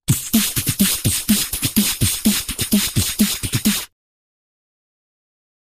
Human Beat Box, Rock Beats, Type 1 - Fast Metal